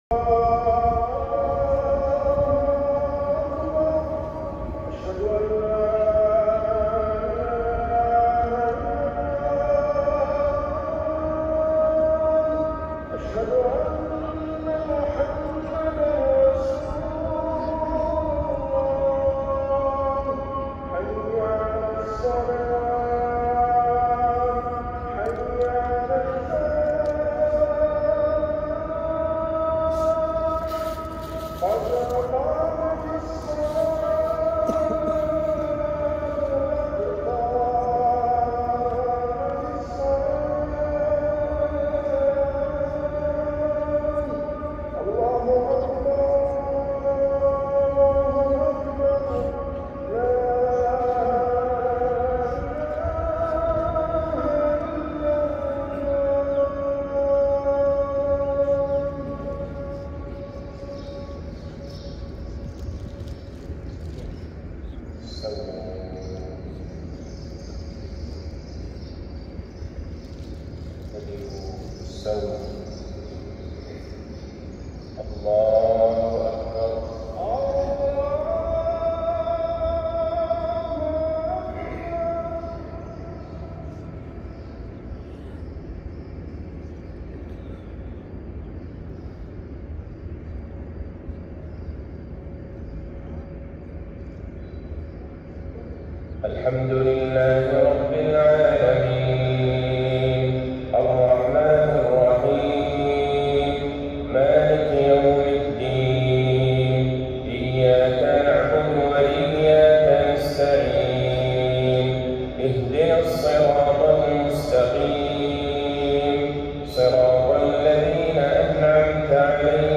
جديد - تسجيل من سطح المسجد النبوي صلاة الفجر ٢٦ ربيع الآخر ١٤٤٤هـ د. عبدالله البعيجان